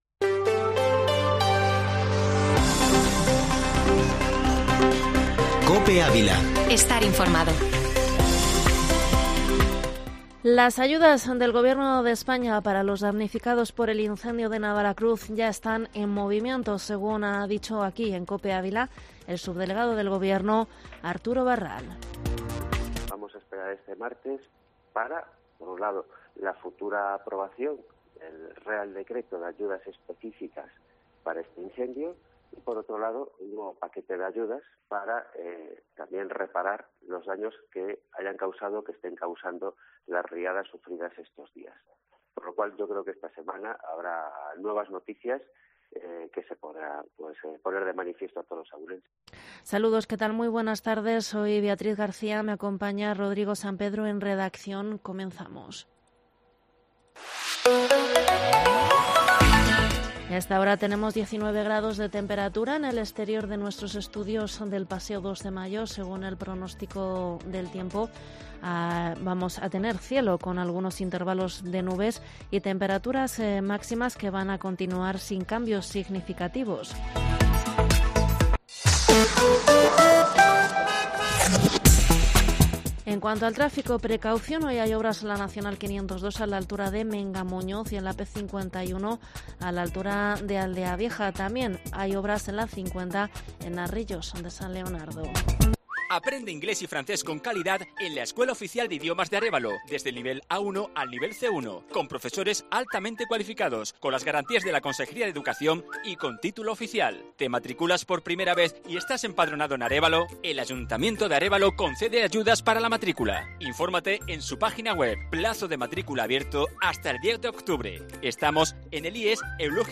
informativo Mediodía COPE ÁVILA 27/09/2021